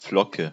Ääntäminen
Ääntäminen France: IPA: /flɔ.kɔ̃/ Haettu sana löytyi näillä lähdekielillä: ranska Käännös Konteksti Ääninäyte Substantiivit 1.